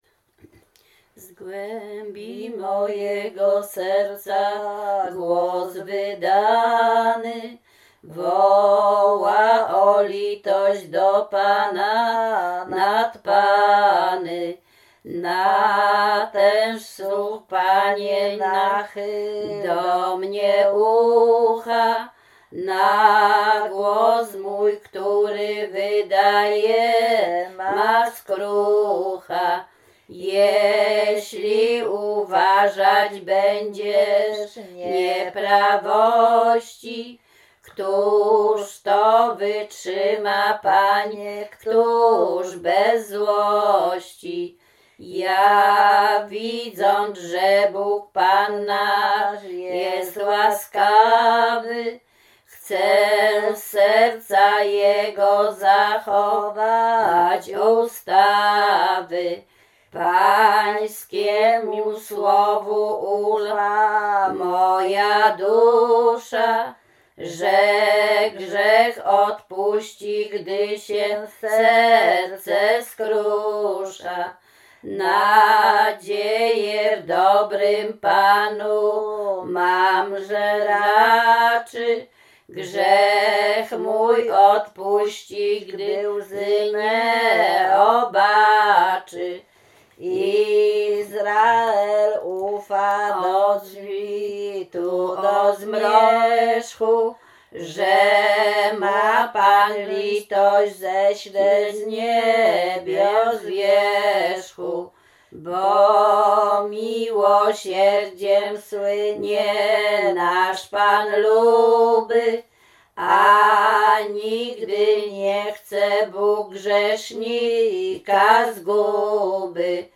Śpiewaczki z Czerchowa
Łęczyckie
Psalm